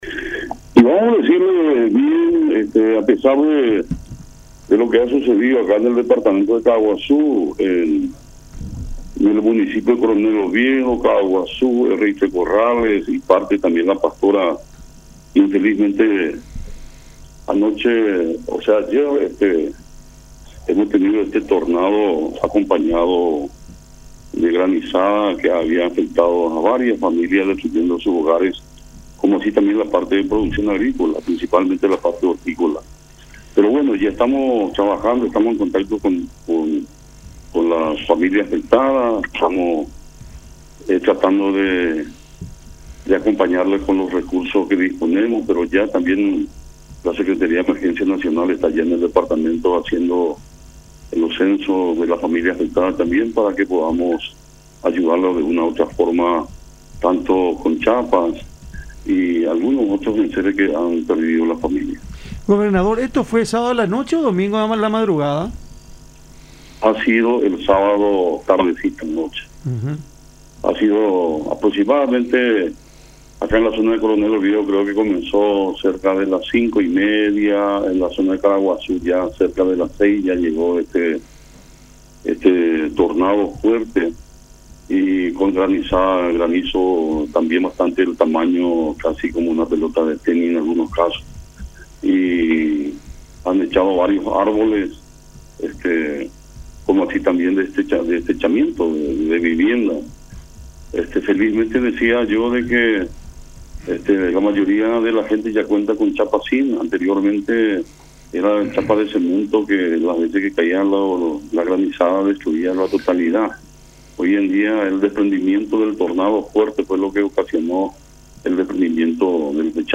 “Tuvimos grandes afectaciones en Coronel Oviedo, ciudad de Caaguazú, RI3 Corrales y La Pastora, luego de las lluvias y la granizada del fin de semana. En la zona de Coronel Oviedo comenzó alrededor de las 17:30 de este sábado esa tormenta, y los granizos tenían el tamaño de pelotas de tenis”, describió Alejo Ríos, gobernador de Caaguazú, en conversación con Cada Mañana por La Unión.